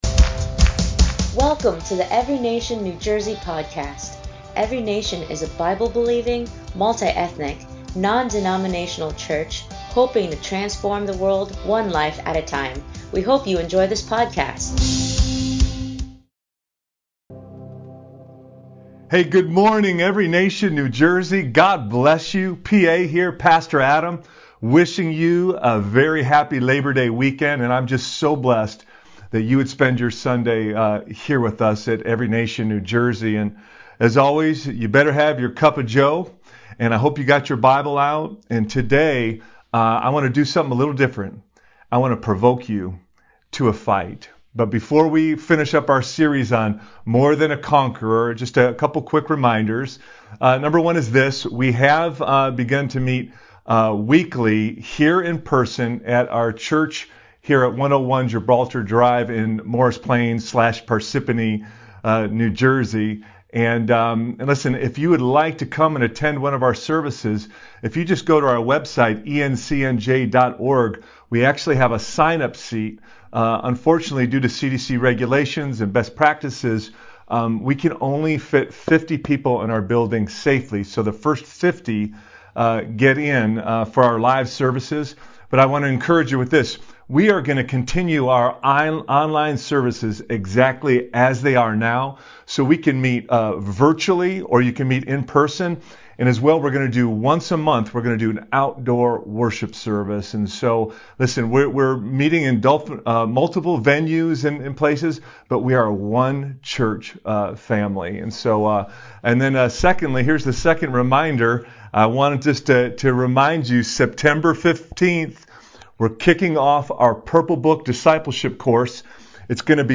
ENCNJ Sermon 9/6/20